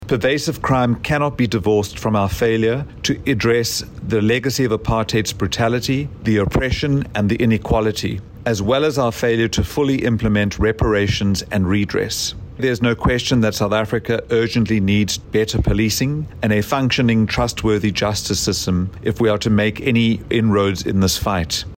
Die sekretaris-generaal van GOOD, Brett Herron, sê die doeltreffende vermindering in misdaad vereis verhoogde wetstoepassing en omvangryke oplossings, met die fokus op maatskaplike en ekonomiese geregtigheid: